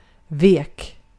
Ääntäminen
Synonyymit svag kraftlös mör rank Ääntäminen Tuntematon aksentti: IPA: /ˈveːk/ Haettu sana löytyi näillä lähdekielillä: ruotsi Käännös Ääninäyte Adjektiivit 1. weak US Vek on sanan vika imperfekti.